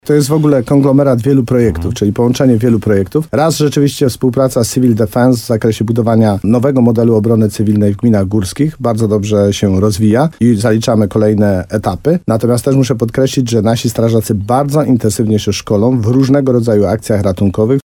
– Oprócz tego, że się szkolimy, to cały czas konsekwentnie budujemy pozytywny wizerunek strażaków – mówi wójt gminy Ochotnica Dolna, Tadeusz Królczyk.